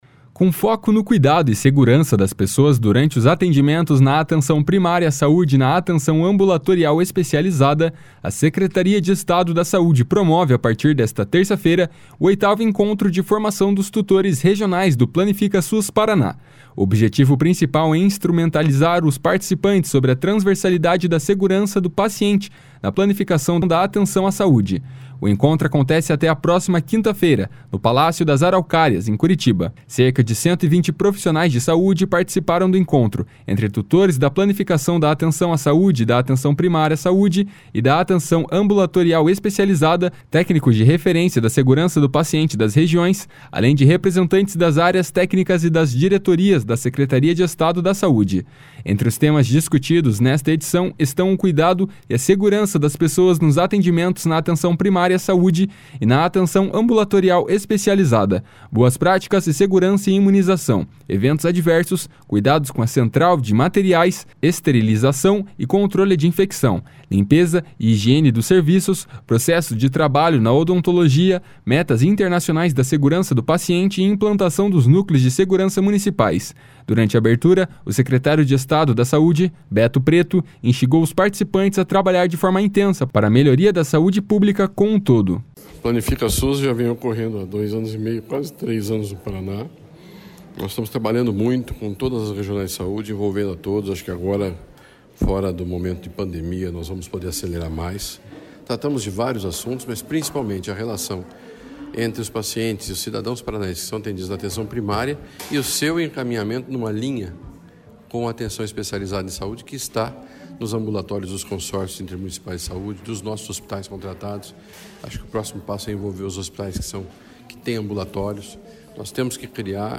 Durante a abertura, o secretário de Estado da Saúde, Beto Preto, instigou os participantes a trabalhar de forma intensa para a melhoria da saúde pública com um todo. // SONORA BETO PRETO //